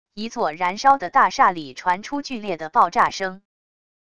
一座燃烧的大厦里传出剧烈的爆炸声wav音频